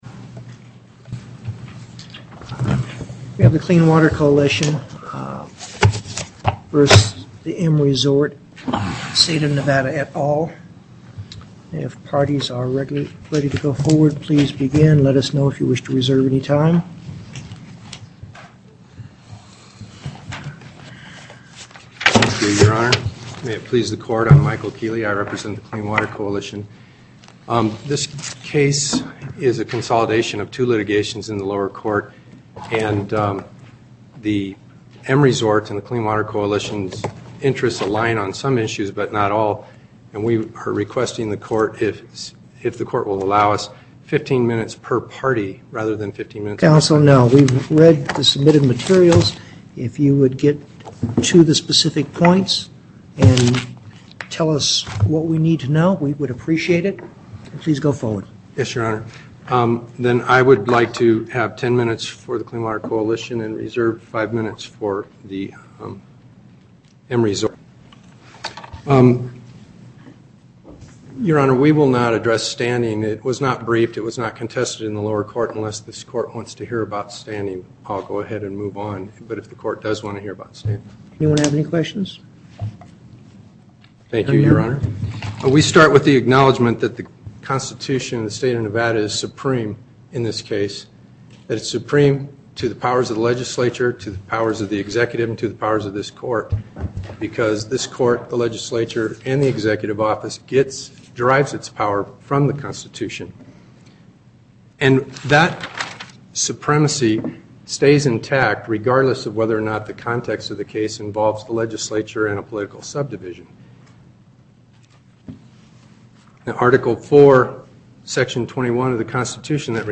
Location: Carson City Before the En Banc Court, Chief Justice Douglas Presiding